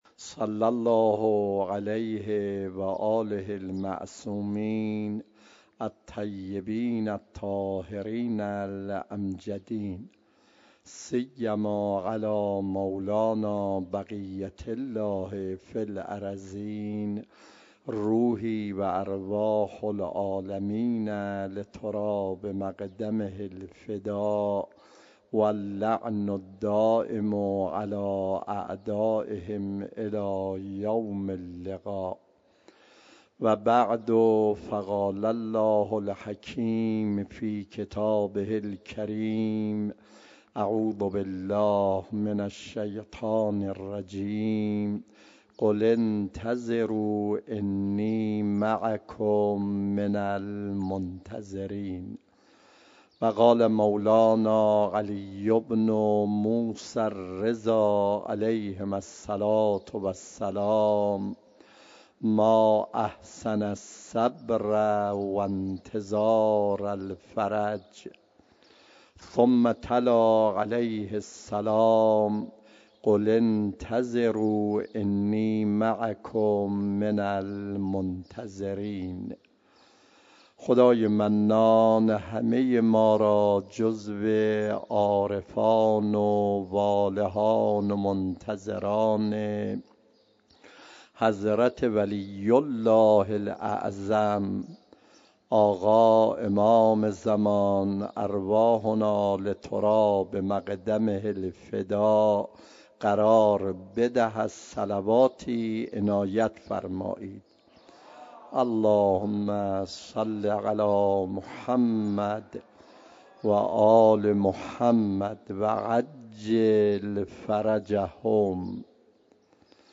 مراسم مسجد مقدس جمکران